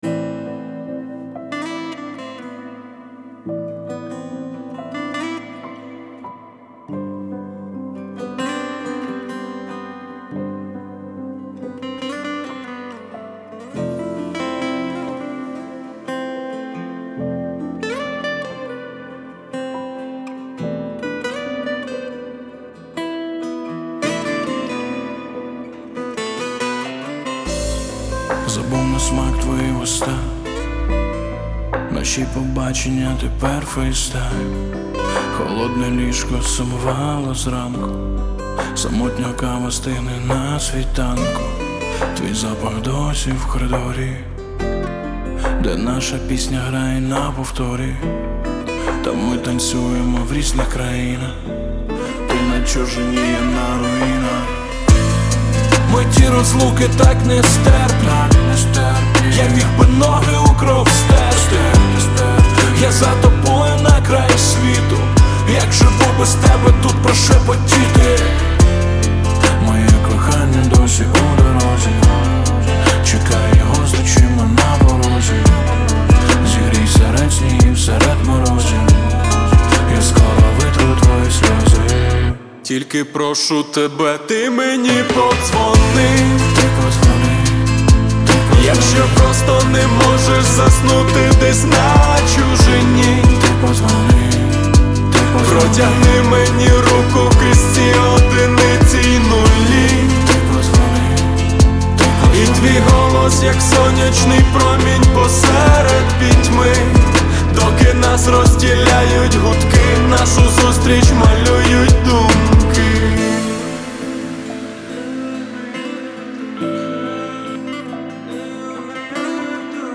• Жанр: Pop